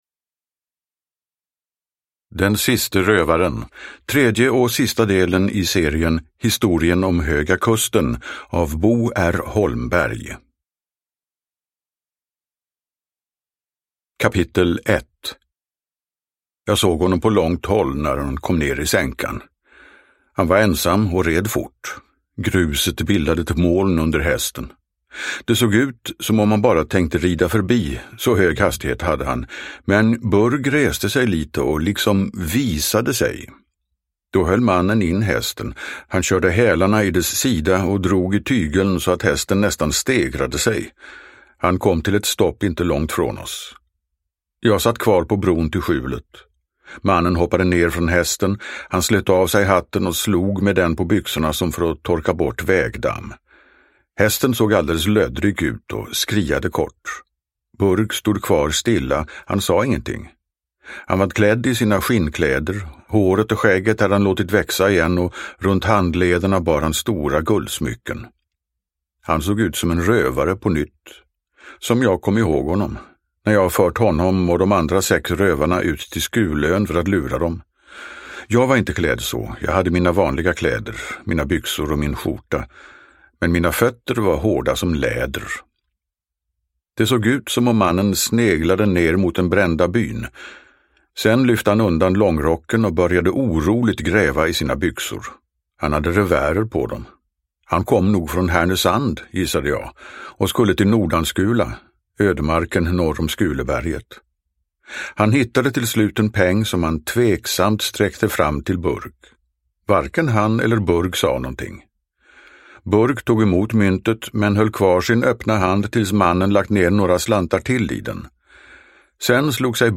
Den siste rövaren – Ljudbok – Laddas ner